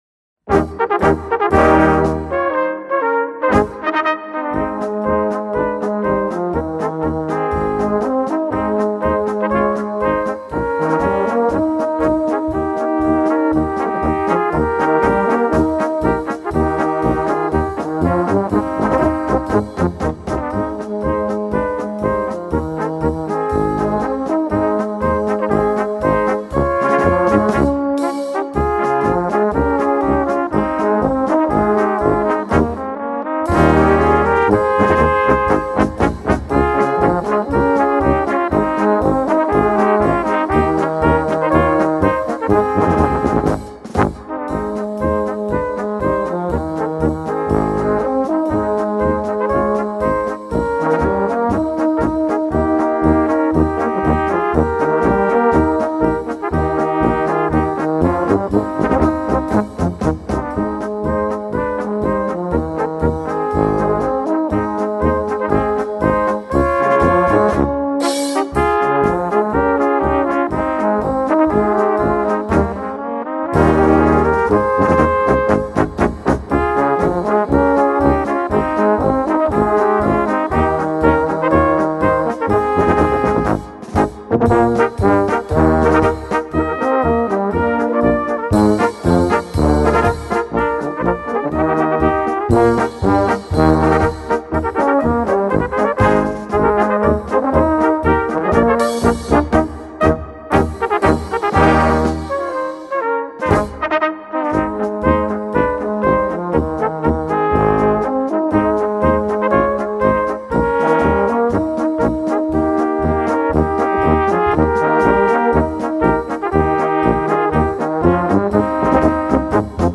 Catégorie Harmonie/Fanfare/Brass-band
Sous-catégorie Polka
Instrumentation Ha (orchestre d'harmonie)